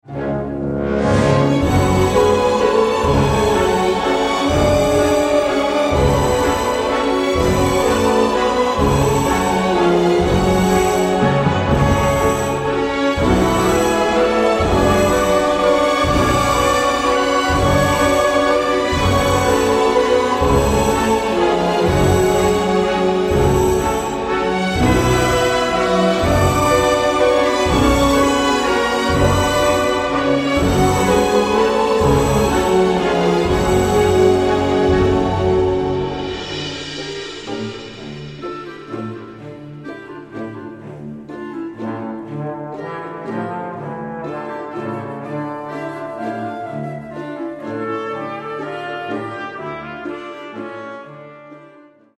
la valse macabre, gothique et tragi-comique